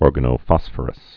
(ôrgə-nō-fŏsfər-əs, -fŏs-fôrəs, ôr-gănə-)